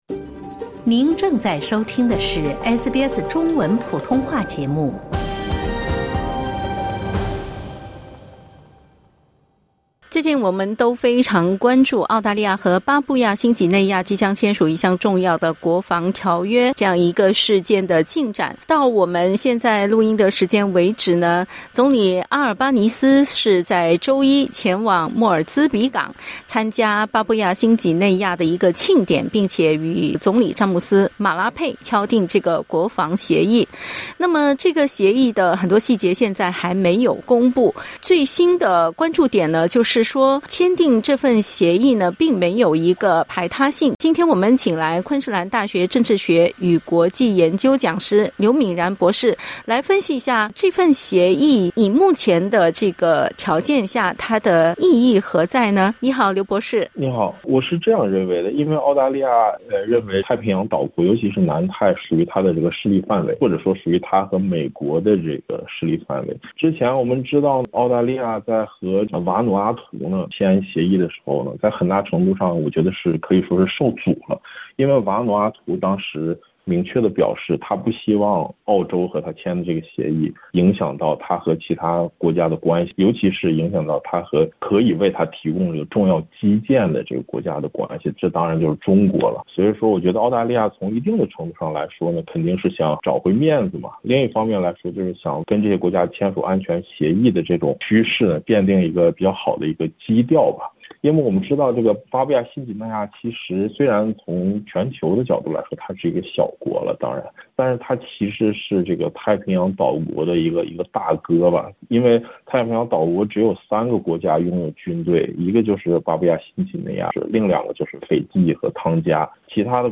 点击音频收听详细采访 本节目仅为嘉宾观点 欢迎下载应用程序SBS Audio，订阅Mandarin。